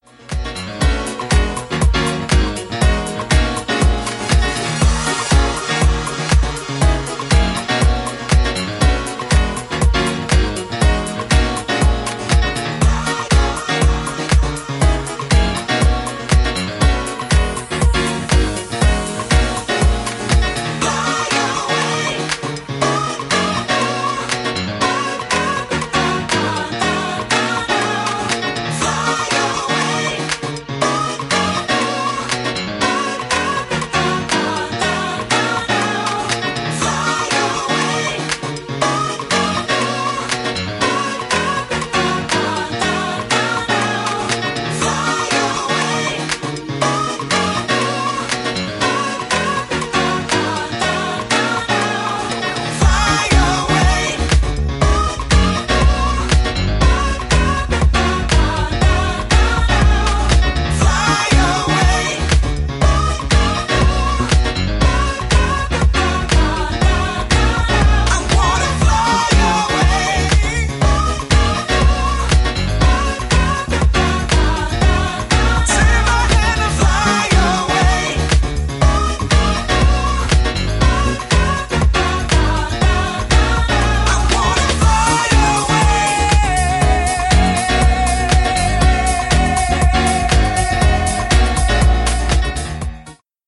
ソウルフル＆ディープなハウスで超超超おすすめの1枚！！
ジャンル(スタイル) HOUSE / SOULFUL HOUSE